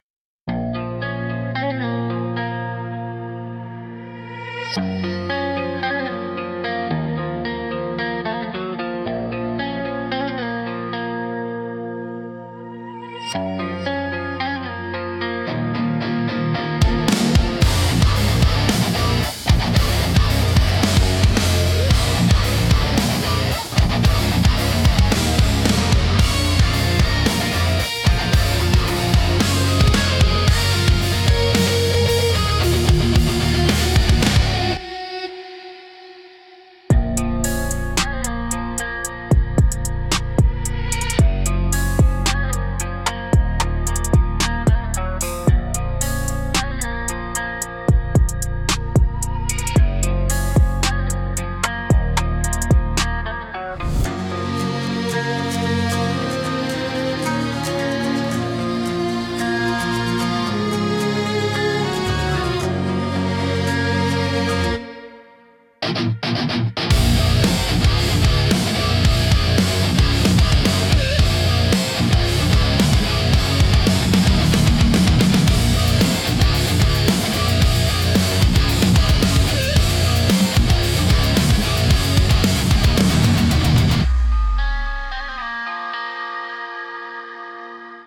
Instrumental -Eulogy for the Exit Ramp